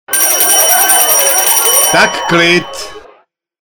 Dal reln zvuky - other sound effectsfor ringtones:
skolni-zvonek-tak-klid.mp3